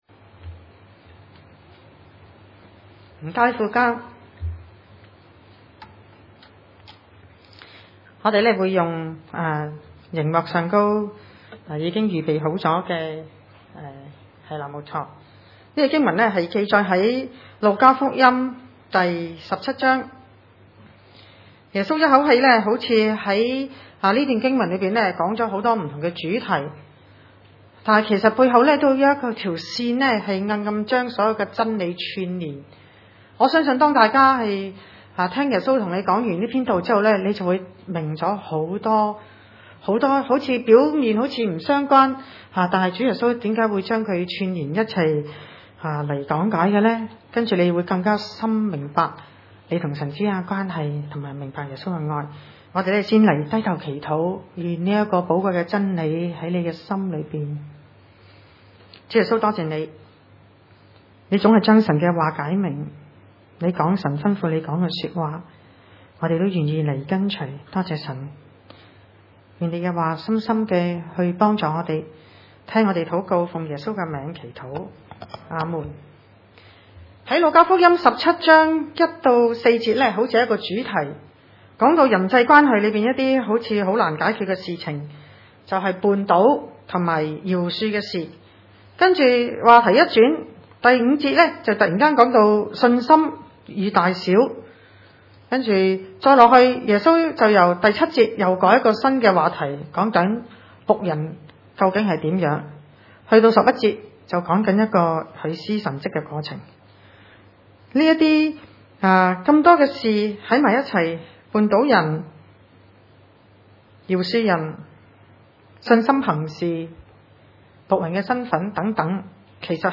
路加福音 17：1-19 崇拜類別: 主日午堂崇拜 1 耶穌又對門徒說：「絆倒人的事是免不了的，但那絆倒人的有禍了 ！